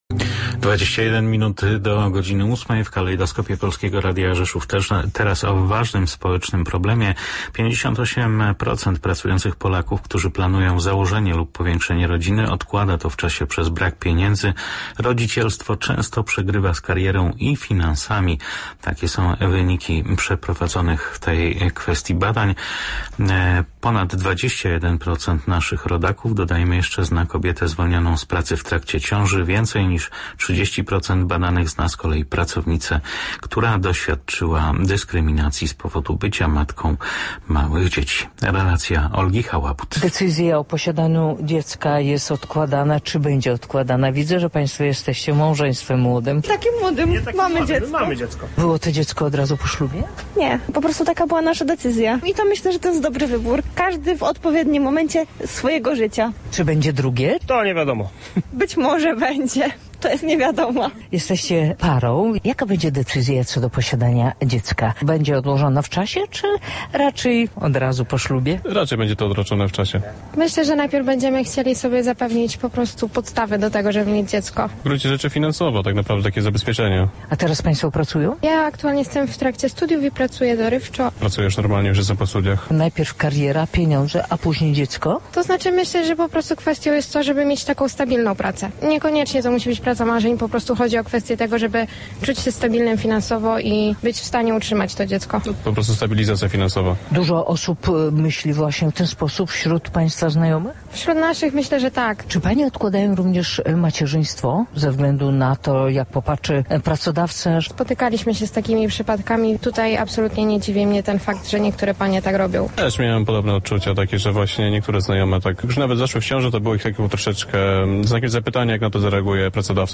Młodzi ludzie coraz częściej odkładają decyzję o posiadaniu dzieci. O powodach takich decyzji, zarówno ekonomicznych, jak i społecznych, opowiedziała na radiowej antenie socjolog